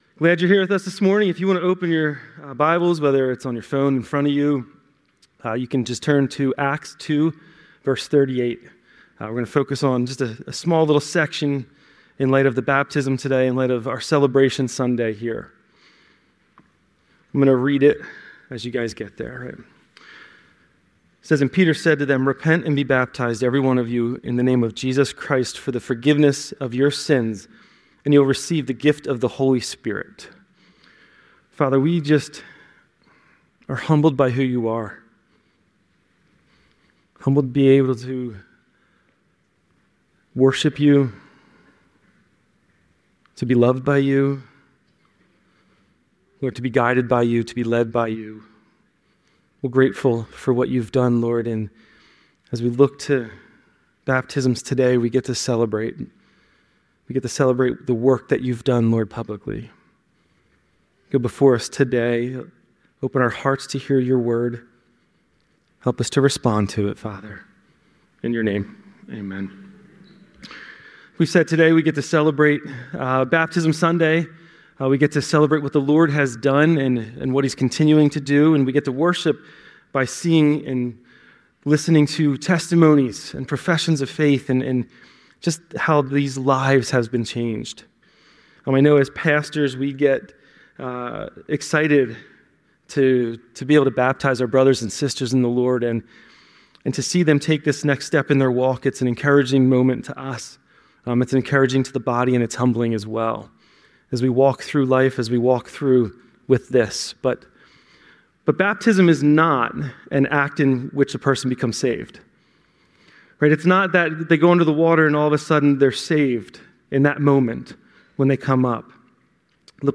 Baptism Service - July 2025
Then hear 11 people share their testimonies and be baptized.